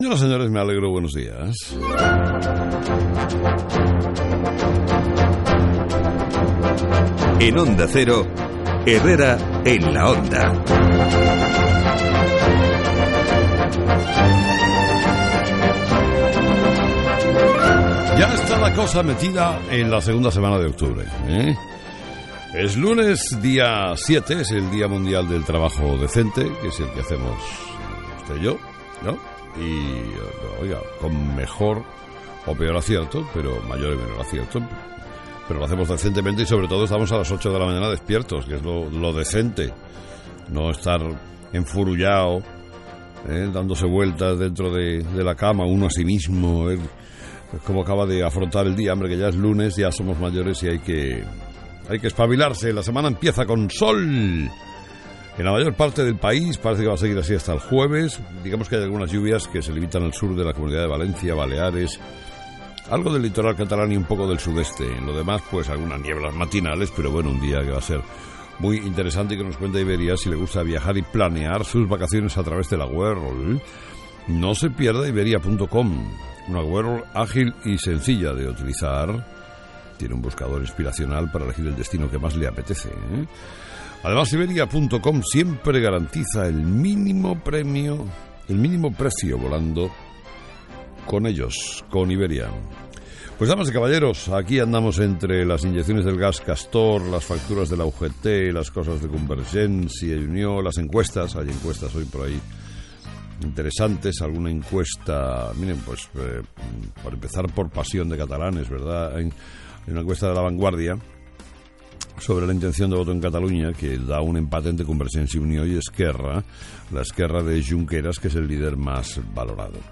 Carlos Herrera comienza la semana hablando de Cataluña.
07/10/2013 Editorial de Carlos Herrera: 'CIU va a tener que dar muchas explicaciones por el caso Palau'